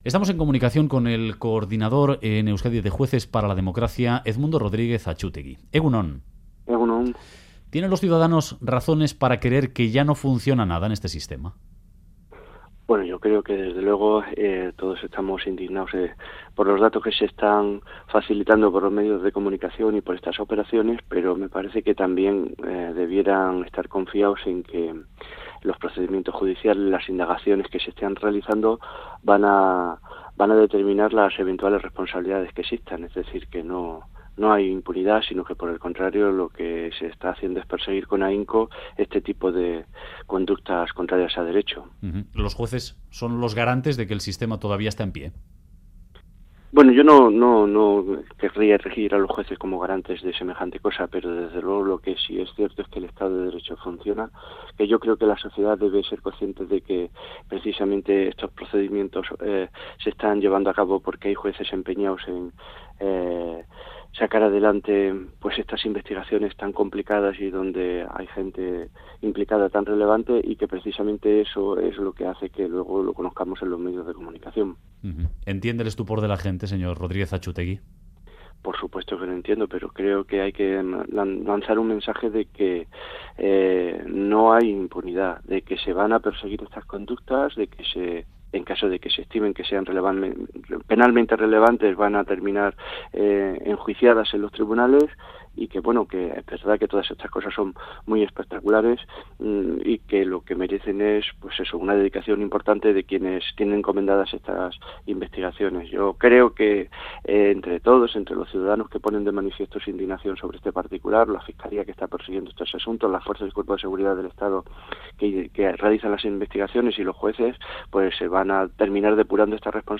Corrupción : En Radio Euskadi, el juez Edmundo Rodríguez Achútegui, ha pedido tranquilidad a la ciudadanía porque no hay impunidad para los corruptos.